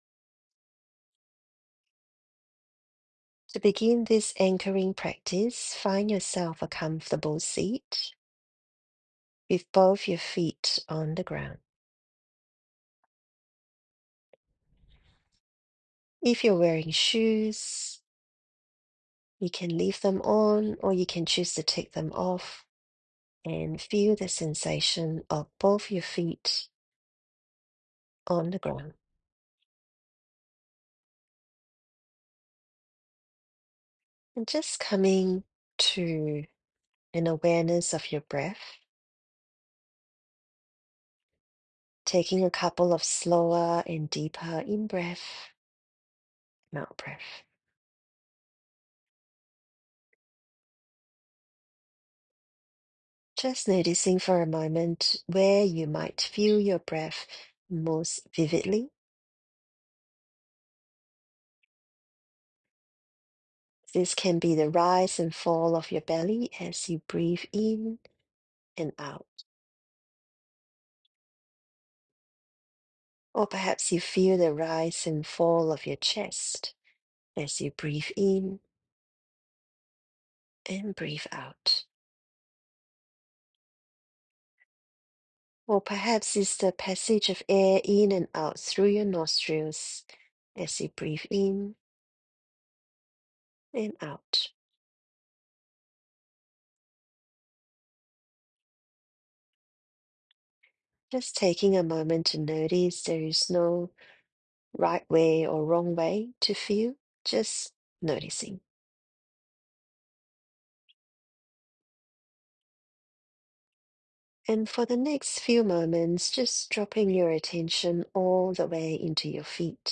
This is a recording of a grounding practice. You can use it as a daily practice or whenever you feel a bit ‘scattered’ and need to come back to yourself.
Grounding.m4a